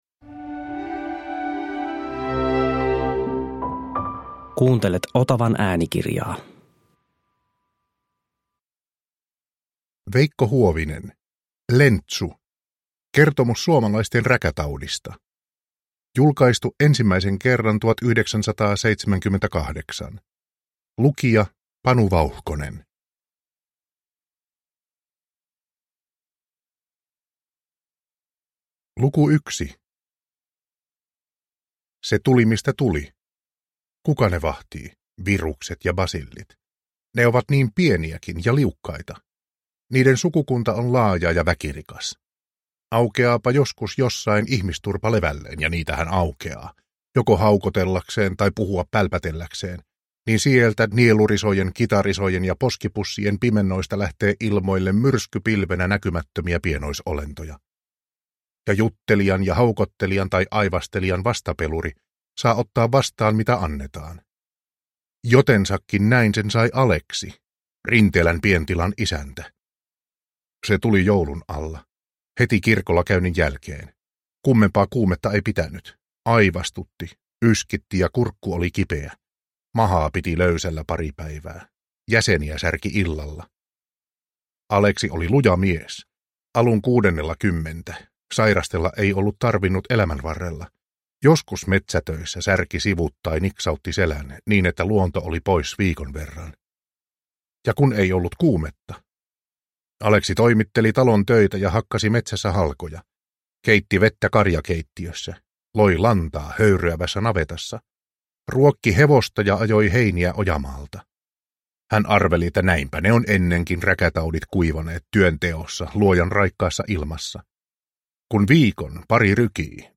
Lentsu – Ljudbok